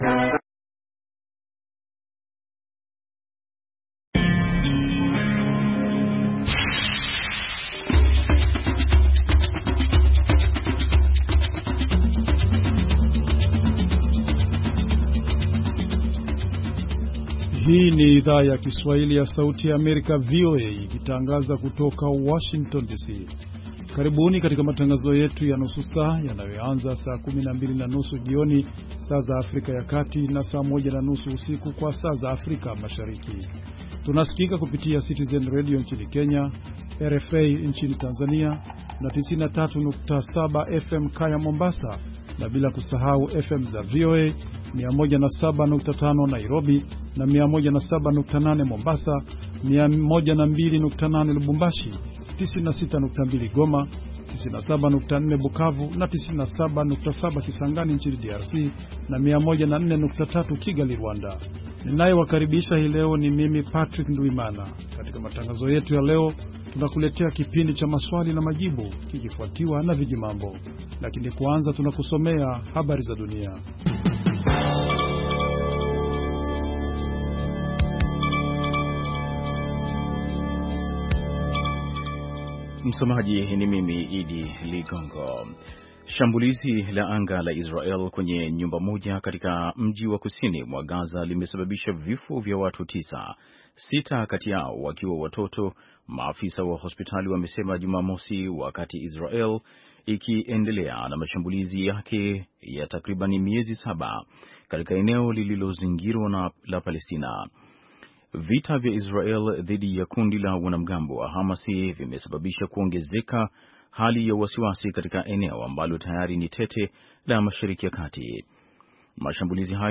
Matangazo ya saa nzima kuhusu habari za kutwa, ikiwa ni pamoja ripoti kutoka kwa waandishi wetu sehemu mbali mbali duniani na kote Afrika Mashariki na Kati, na vile vile vipindi na makala maalum kuhusu afya, wanawake, jamii na maendeleo.